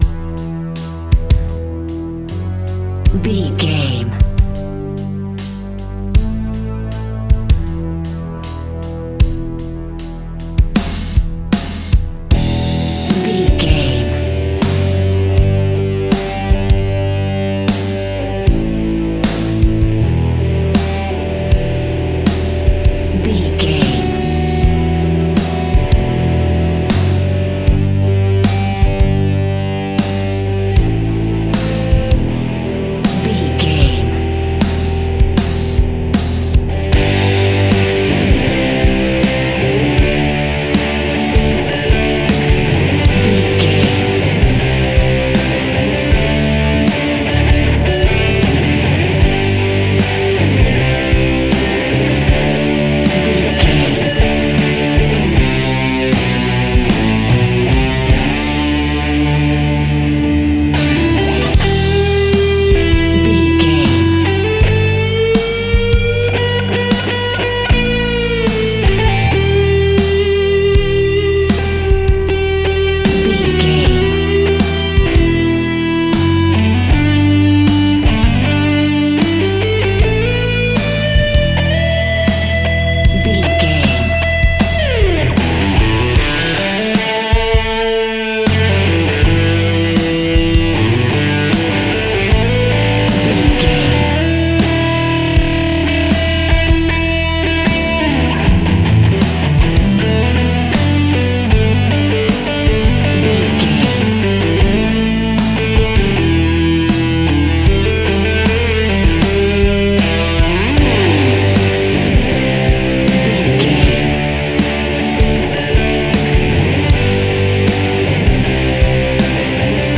Epic / Action
Aeolian/Minor
hard rock
blues rock
distortion
rock instrumentals
rock guitars
Rock Bass
heavy drums
distorted guitars
hammond organ